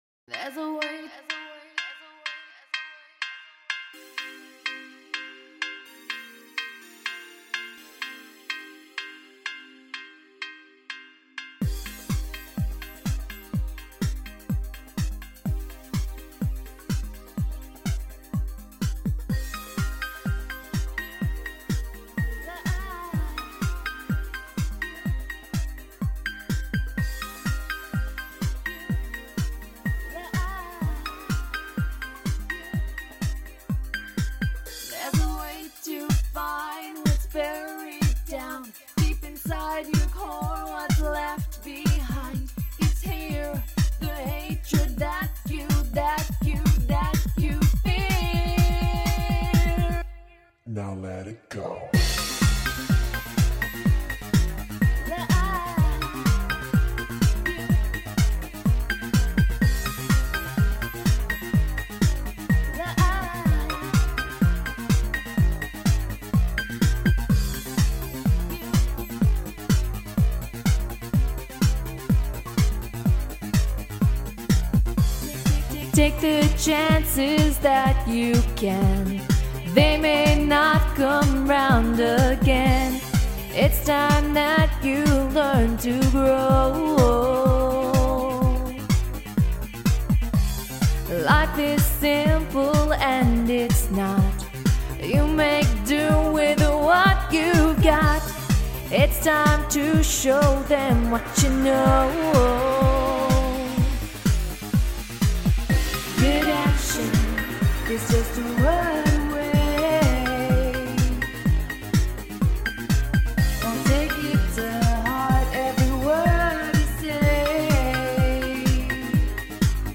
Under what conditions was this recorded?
I hope you enjoy it even a little, despite the obvious flaws of someone who has no idea of what they're doing, messing around in GarageBand haha!